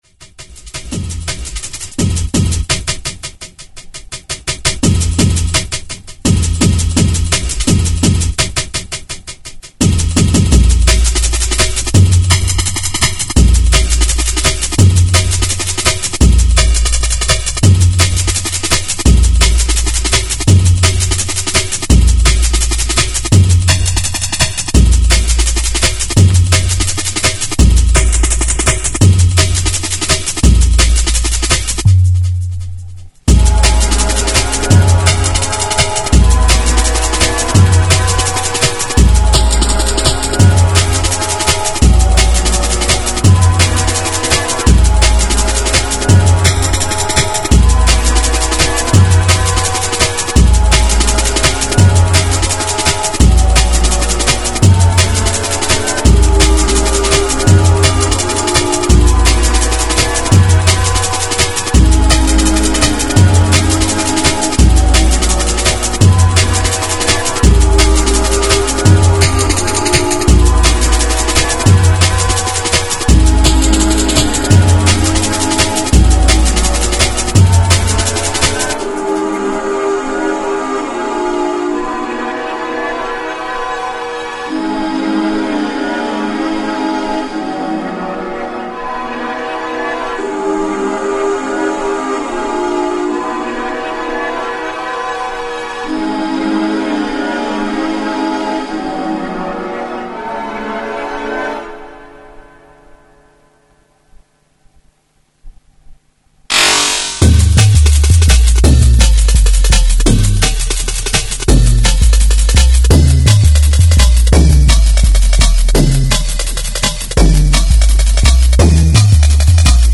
fast paced Jungle music